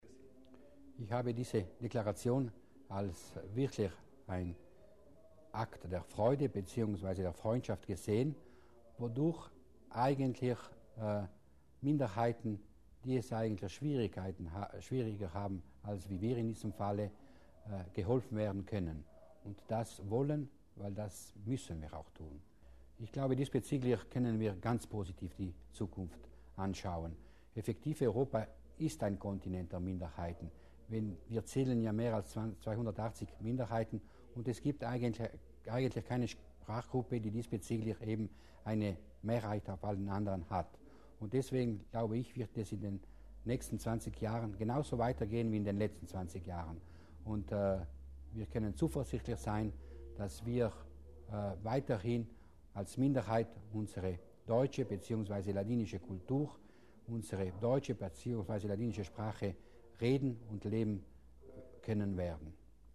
Landesrat Mussner über das Minderheitenfestival in Aosta